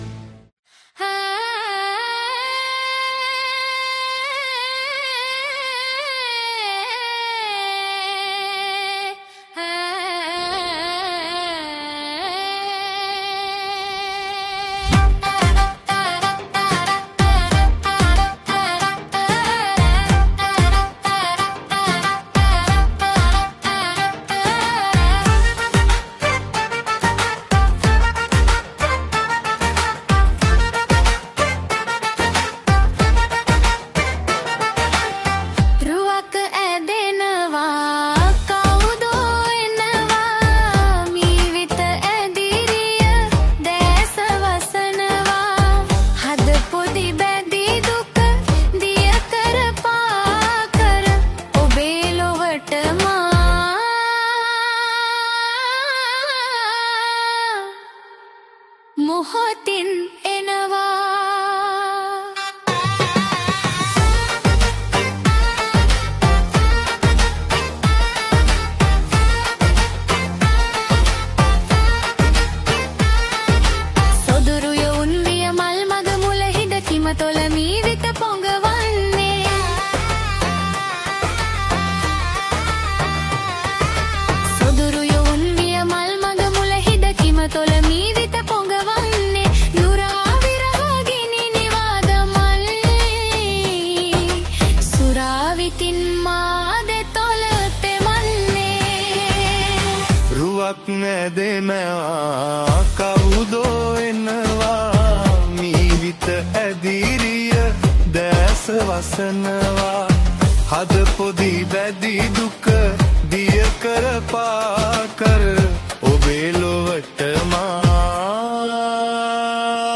Releted Files Of Sinhala Dj Nonstop Songs